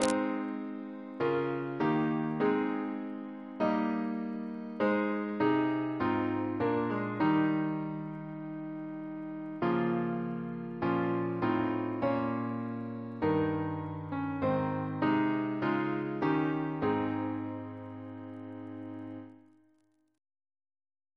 Double chant in A♭ Composer: James Turle (1802-1882), Organist of Westminster Abbey Reference psalters: ACB: 329; PP/SNCB: 208; RSCM: 137